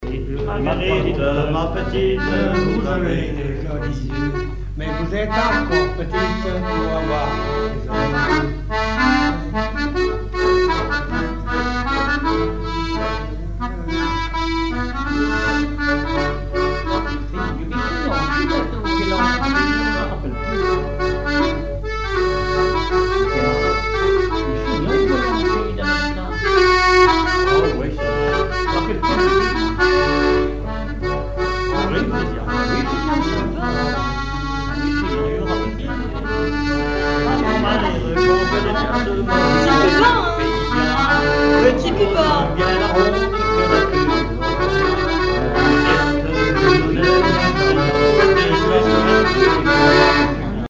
Chants brefs - A danser
danse : mazurka
chansons et instrumentaux
Pièce musicale inédite